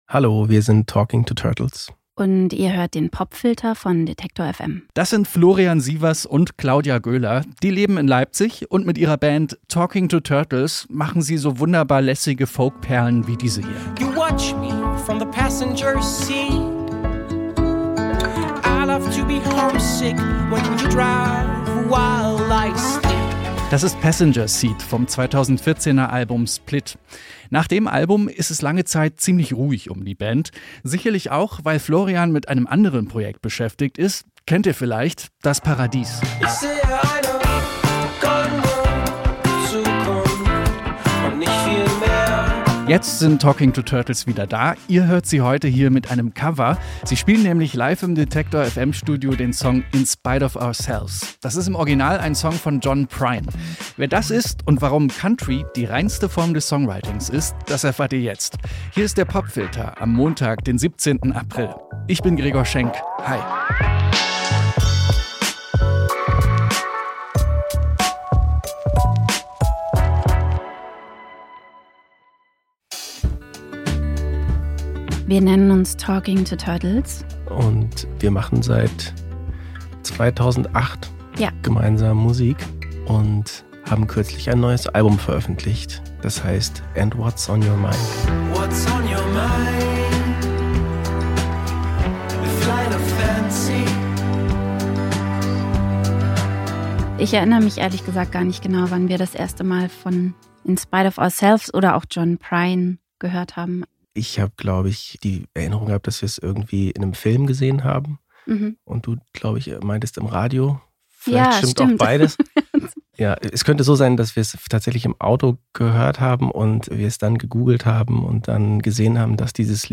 Folk-Band